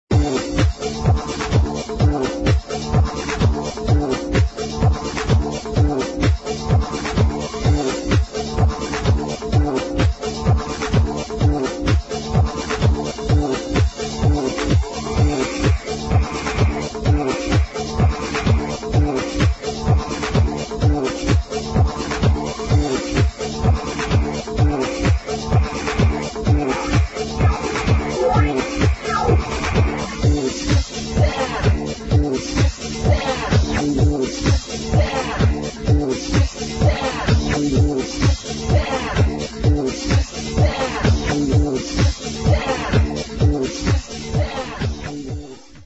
[ BIG BEAT / BREAKBEAT / DISCO ]